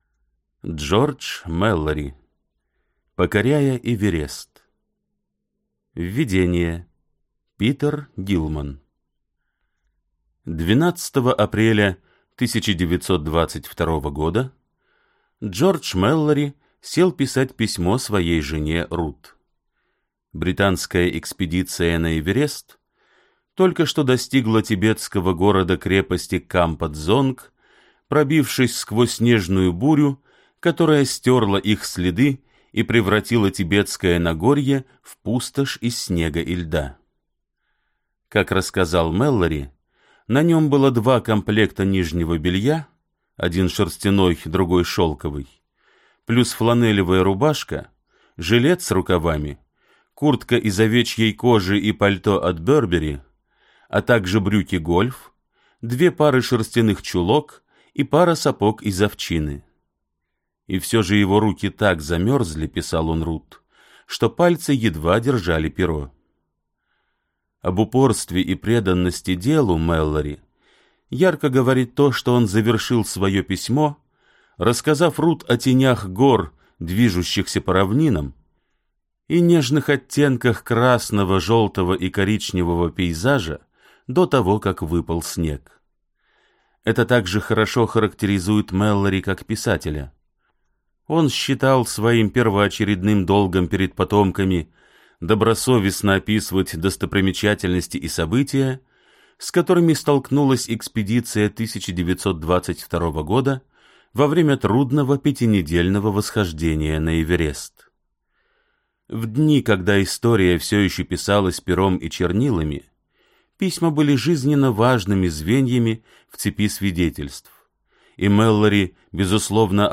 Аудиокнига Покоряя Эверест | Библиотека аудиокниг